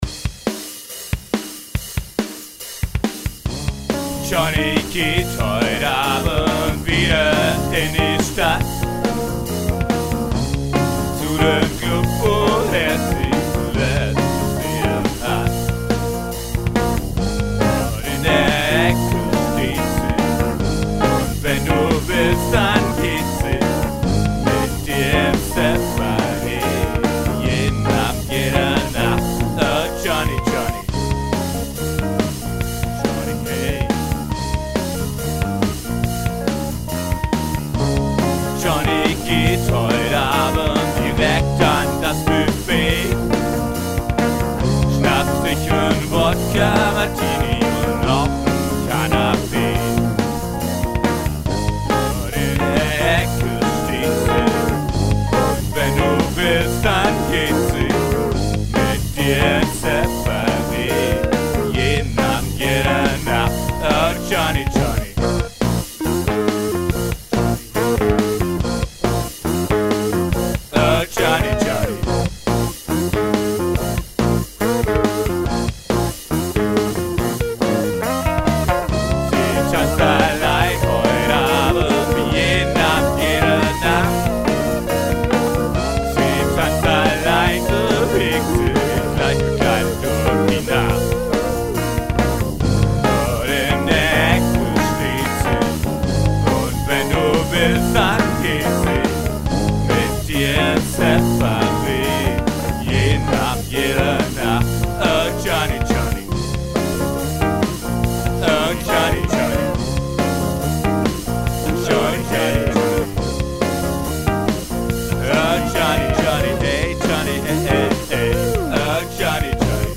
Blues & Folk, Deltablues